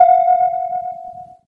Free Samples : samples de effects .Efectos especiales,sonidos extrańos..